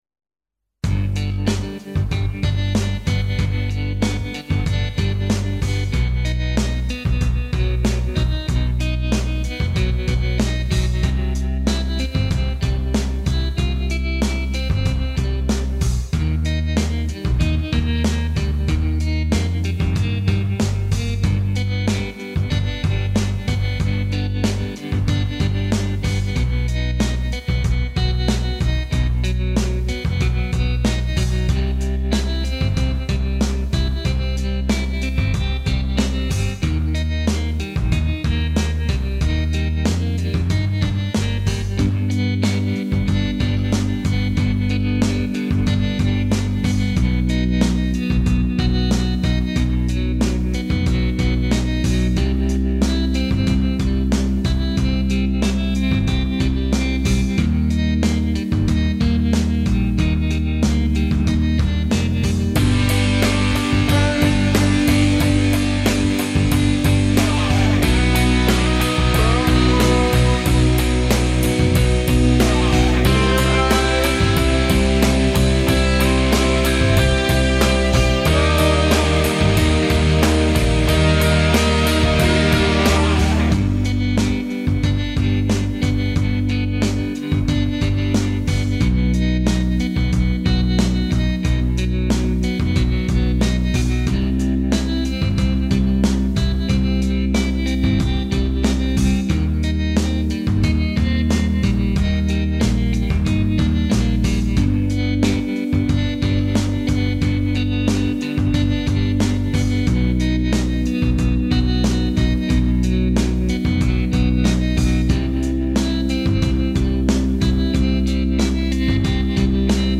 melodieux - piano - aerien - pop